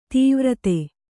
♪ tīvrate